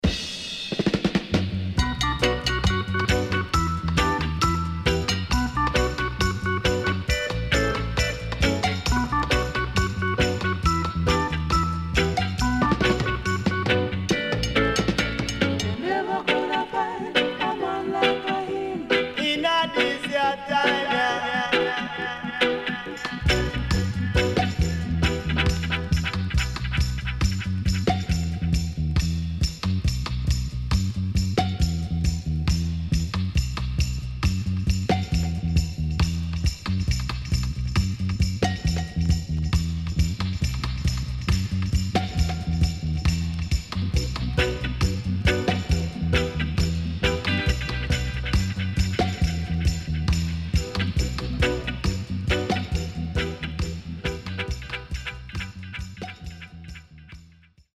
Killer Roots Vocal
SIDE A:軽いヒスノイズ入ります。